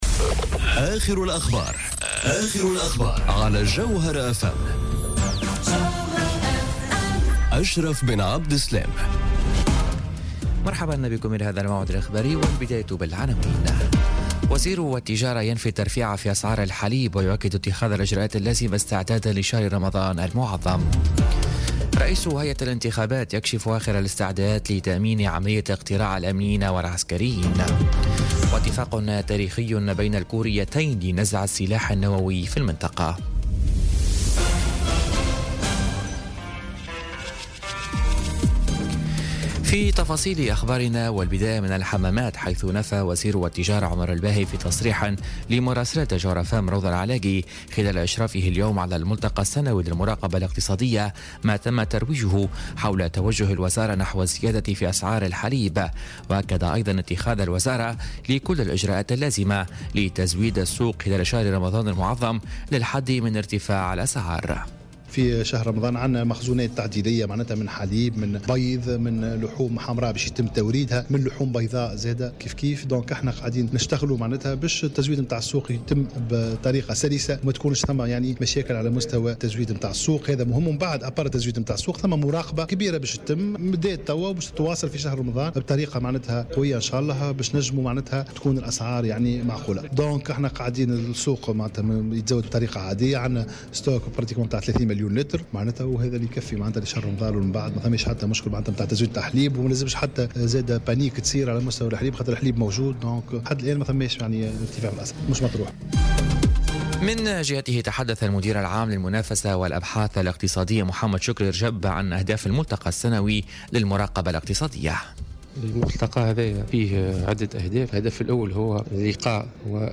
نشرة أخبار منتصف النهار ليوم الجمعة 27 أفريل 2018